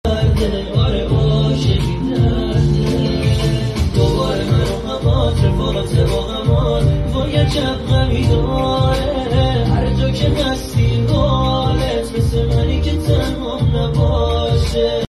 آهنگ جدید و غمگین ۹۹